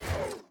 wheel_disappear_02.mp3